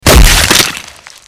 Sound Effects
Impactful Damage